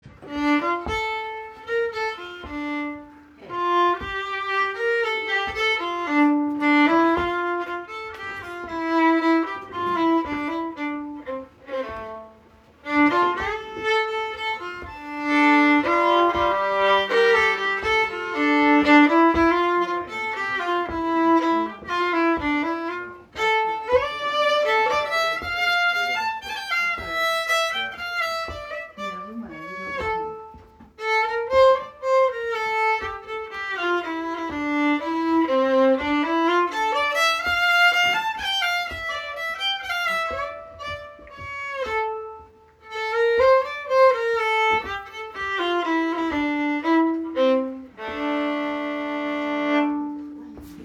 Sessions are open to all instruments and levels, but generally focus on the melody. Recordings of all the tunes we learn are archived here for future reference.
Composer Susie Secco Type Waltz Key D minor Recordings